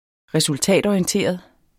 Udtale [ -ɒiənˌteˀʌð ]